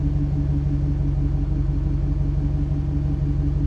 v8_05_idle.wav